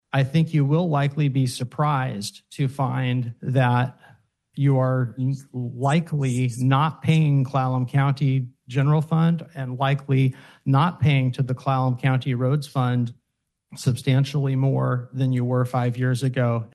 Commissioner Mark Ozias told the crowd he thinks a lot of the property tax crunch is due to all the other items that are on the bill and not just the county’s portion.